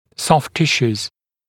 [sɔft ‘tɪʃuːz] [-sjuː][софт ‘тишу:з] [-сйу:]мягкие ткани